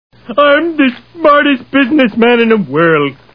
The Simpsons [Homer] Cartoon TV Show Sound Bites